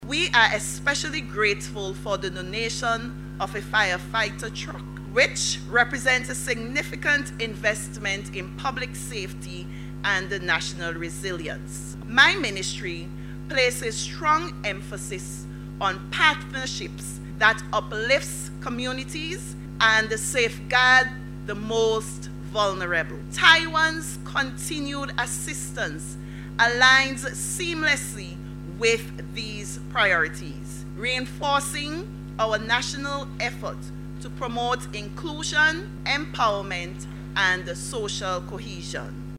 Speaking at a recent handing-over ceremony on Tuesday, Minister John emphasized that through various initiatives and partnerships, the ministry is dedicated to uplifting vulnerable populations and fostering social cohesion.